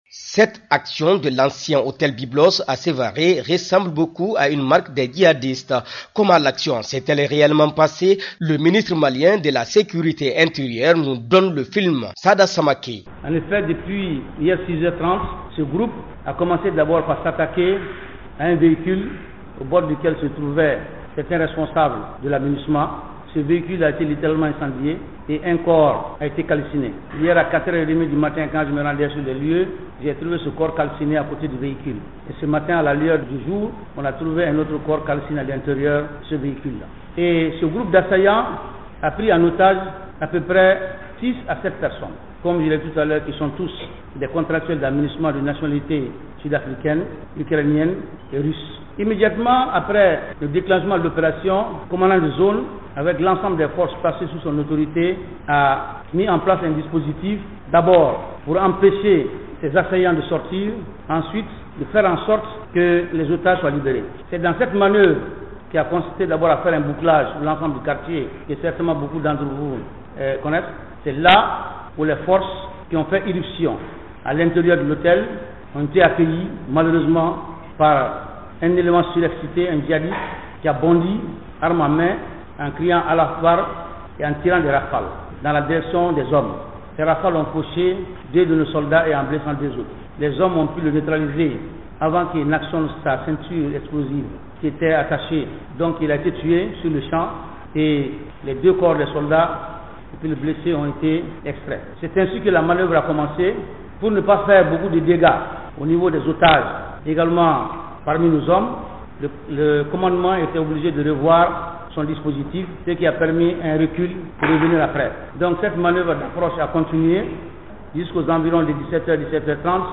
De Mopti, notre correspondant